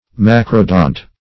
Search Result for " macrodont" : The Collaborative International Dictionary of English v.0.48: Macrodont \Mac"ro*dont\, a. [Macro- + Gr.